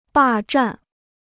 霸占 (bà zhàn) Free Chinese Dictionary